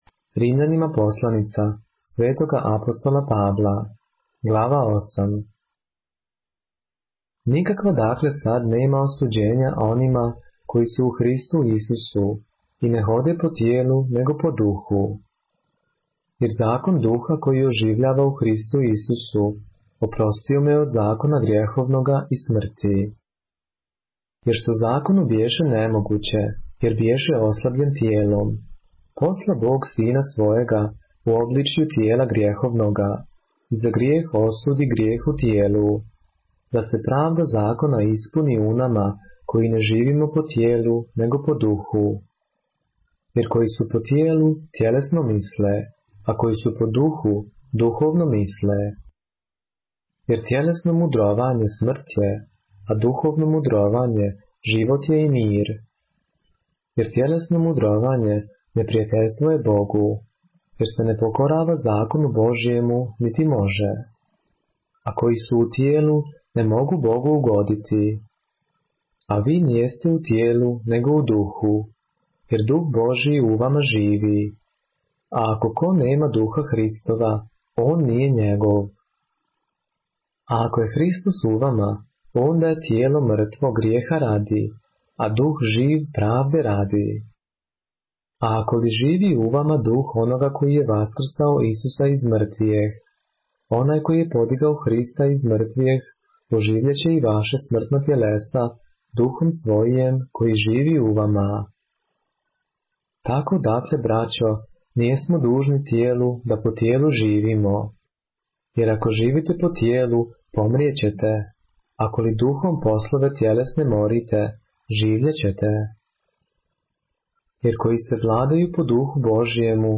поглавље српске Библије - са аудио нарације - Romans, chapter 8 of the Holy Bible in the Serbian language